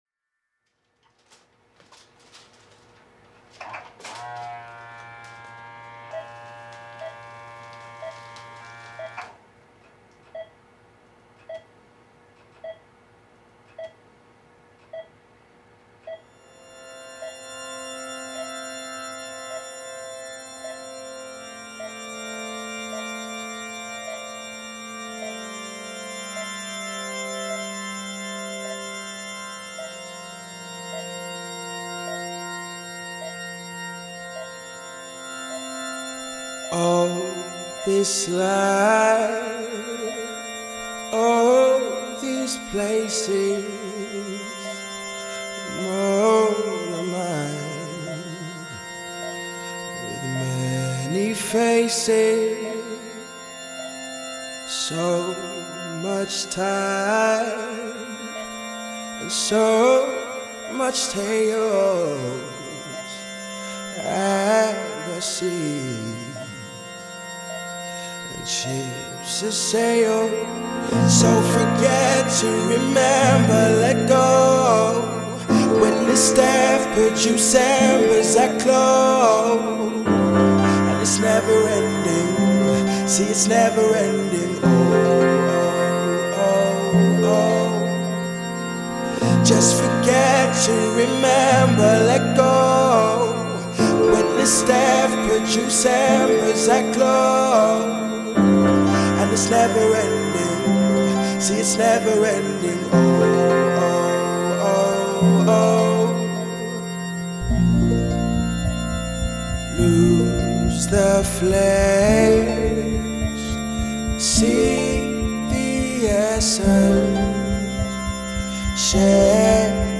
voice has very strong emotive abilities